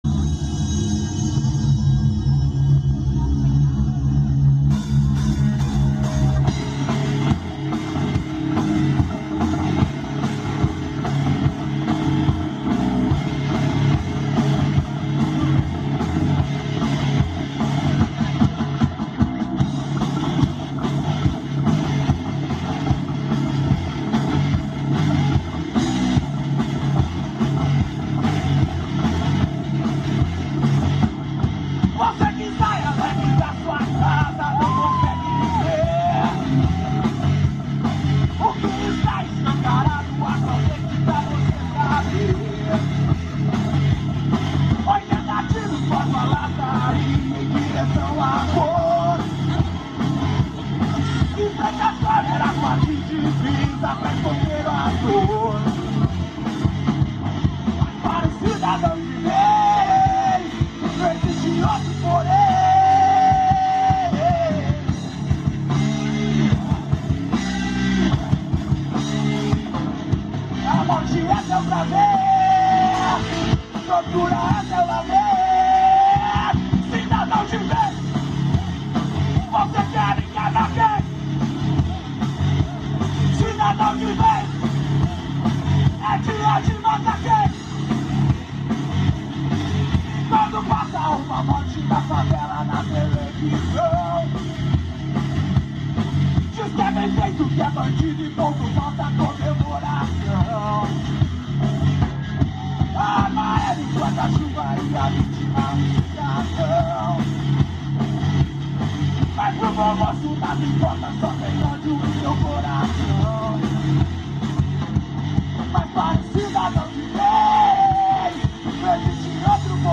EstiloGrunge